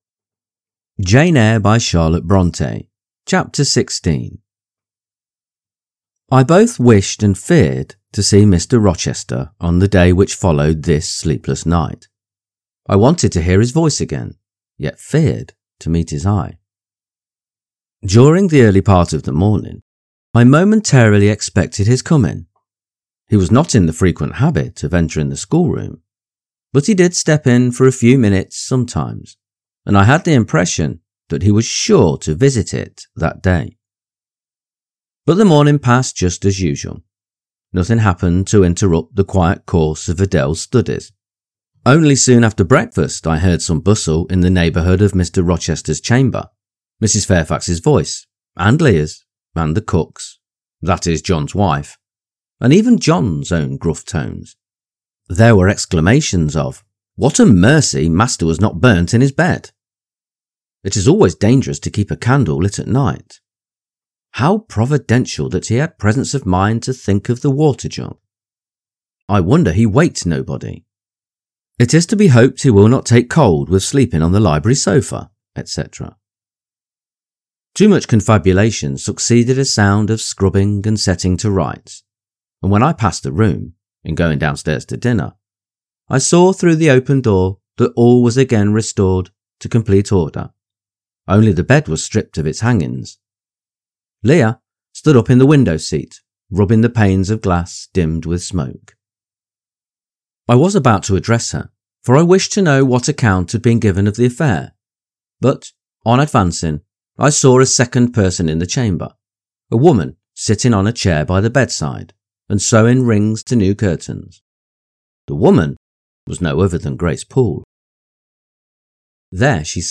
Jane Eyre – Charlotte Bronte – Chapter 16 | Narrated in English - Dynamic Daydreaming